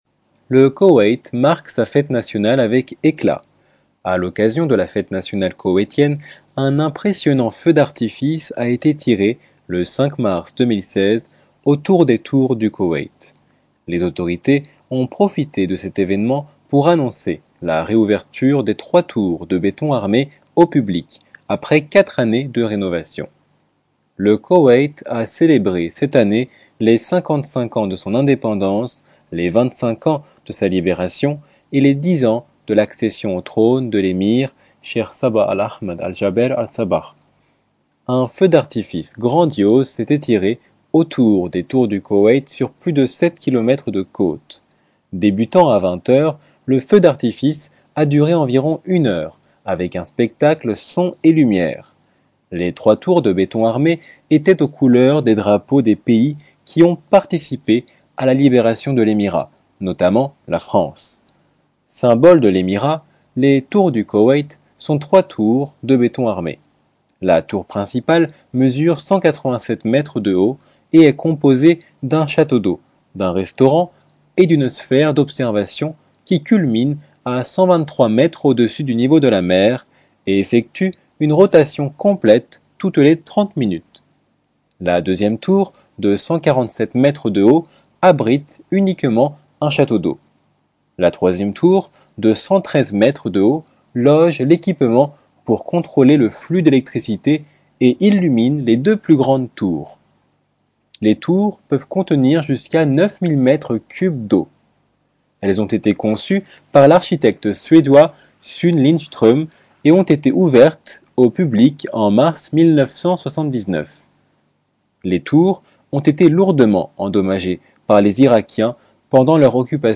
Extrait du feu d'artifice au Koweït du 5 mars 2016
Feu d'artifice au Koweït.mp3 (1.33 Mo)
Un feu d'artifice grandiose s'est étiré autour des tours du Koweït sur plus de 7 km de côtes. Débutant à 20h00, le feu d'artifice a duré environ une heure, avec un spectacle son et lumière.